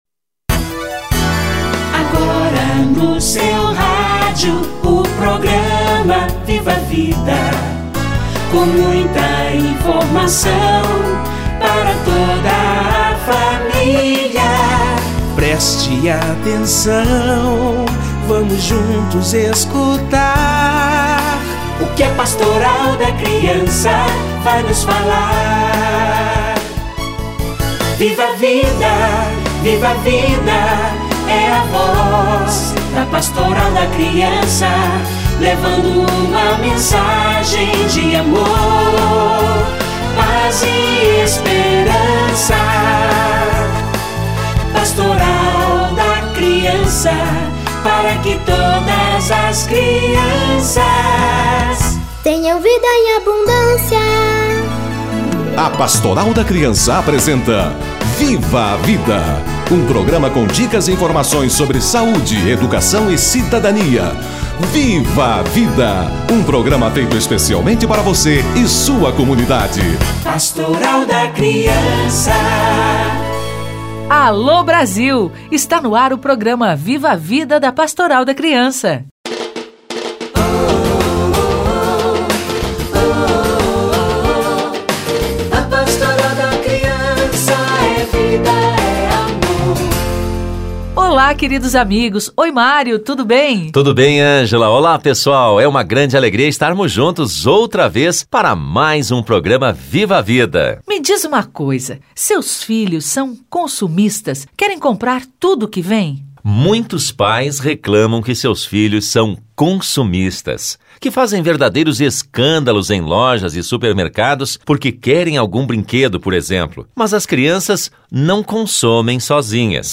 A criança e o consumo - Entrevista